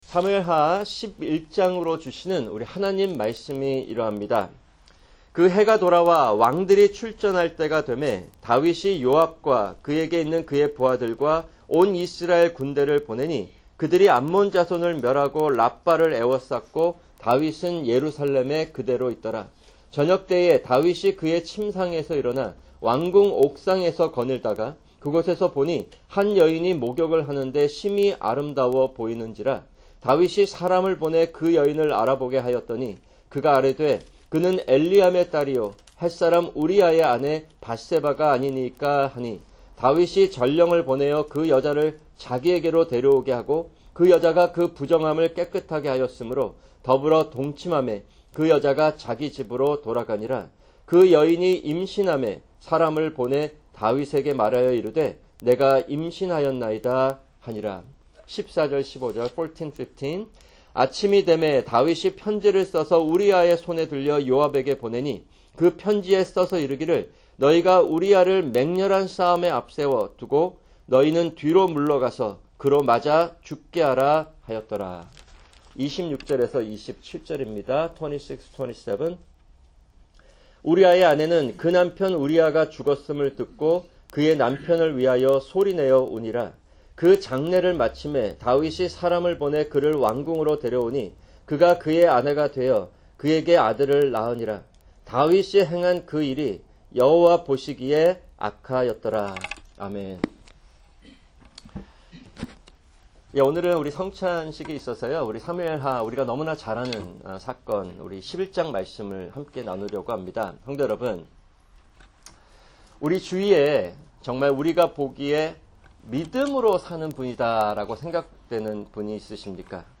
[주일 설교] 사무엘하 11:1-27